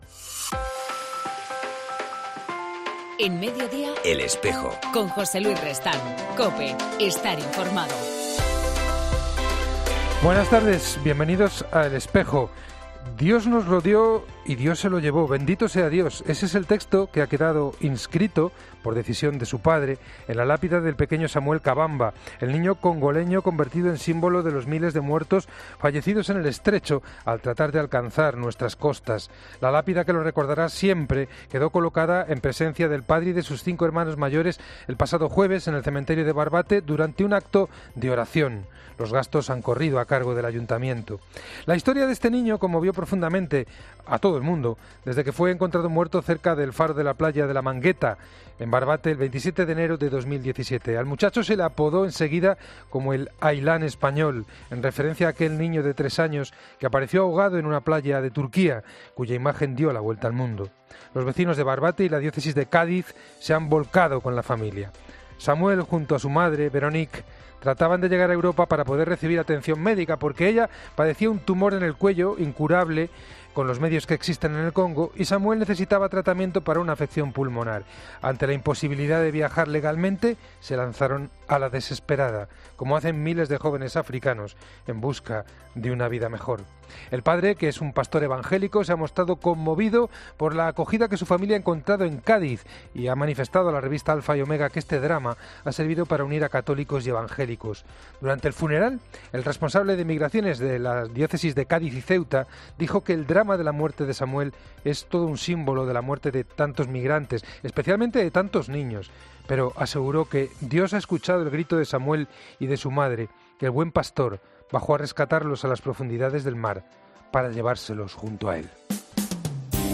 En EL Espejo del 9 de enero entrevistamos a Mons. Ángel Garachana, Presidente de la Conferencia Episcopal de Honduras